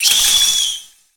Cri de Galvagla dans Pokémon HOME.